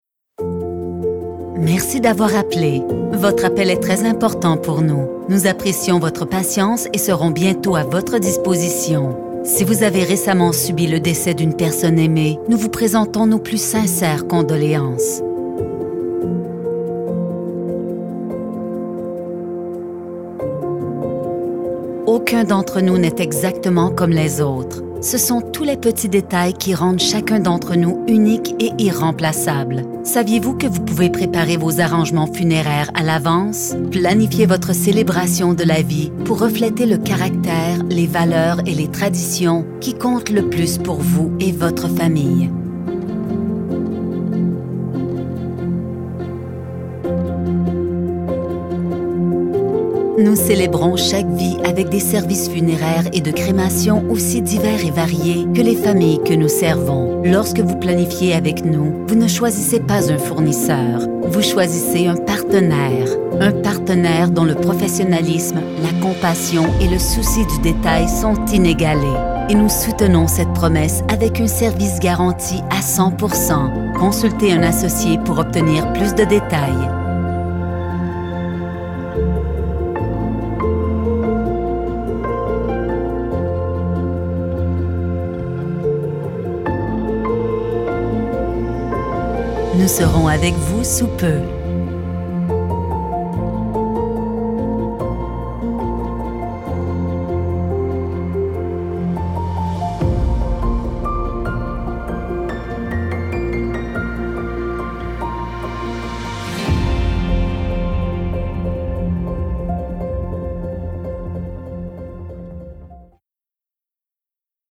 Dignity Memorial On-Hold Message
dignite-on-hold-standard-french-canadian